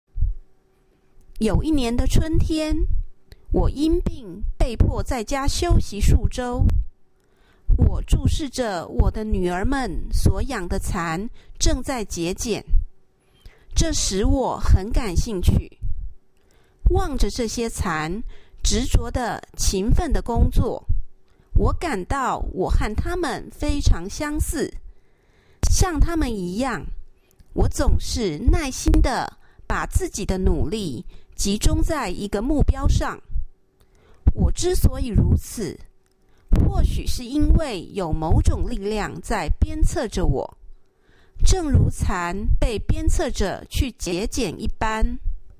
Task 3 Passage Reading
Taiwan Sample: